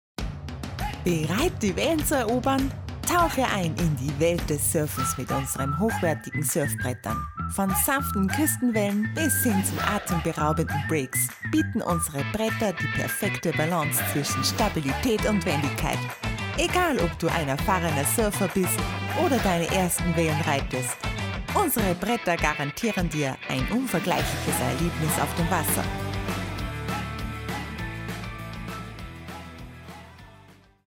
Female
Österreichisches Deutsch, warmherzig, freundlich, charmant, beruhigend, energisch
Radio Commercials
Advertisement Surfboards
1003Werbung_Surfbretter.mp3